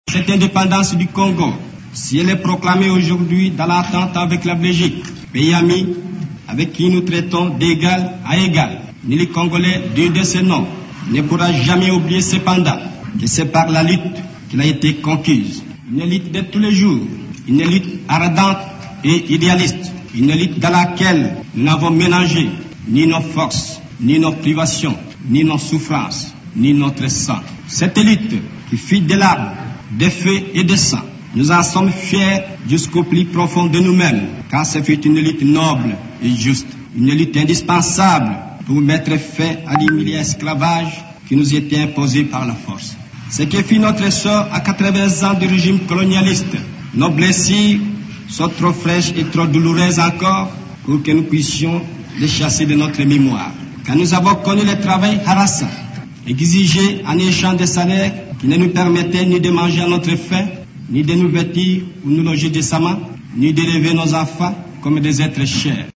Le discours qu’il avait prononcé le 30 juin 1960, au cours de la cérémonie célébrant l’indépendance de l’ex-Congo belge, est resté dans les annales.